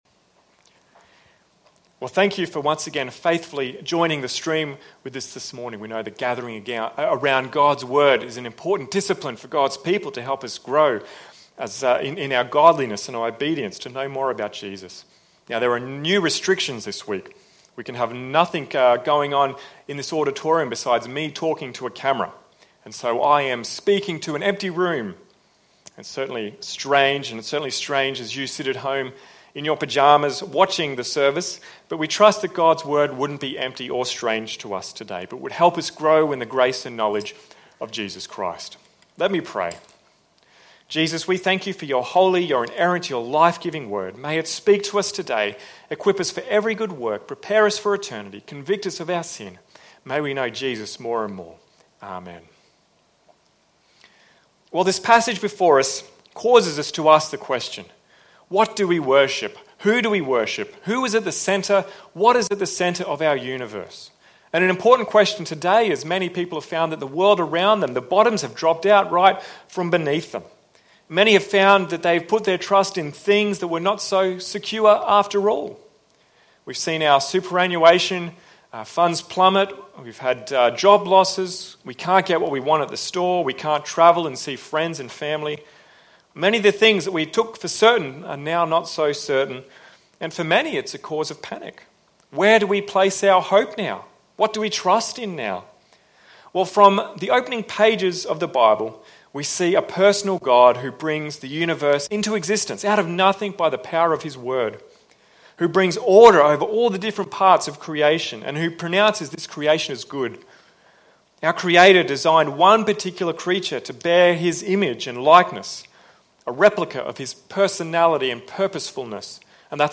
Sermons | Mount Isa Baptist Church